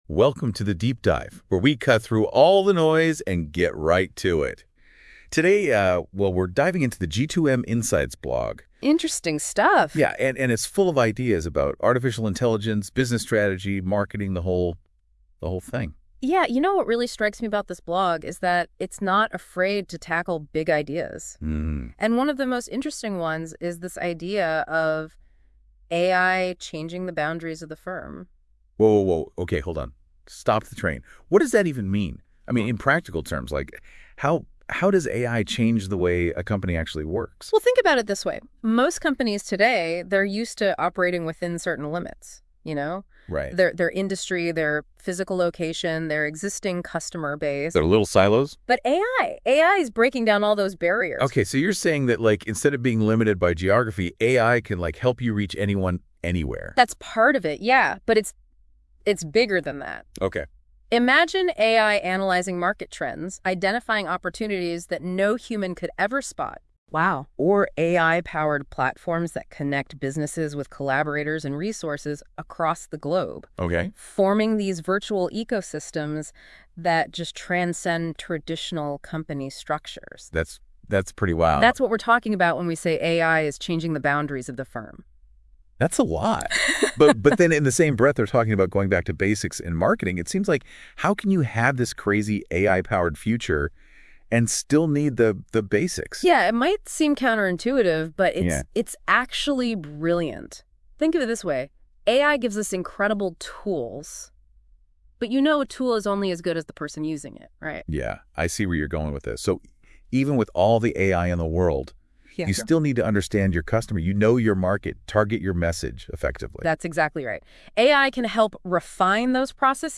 This podcast episode was generated using AI combined with original ideas and content from the G2M Insights blog.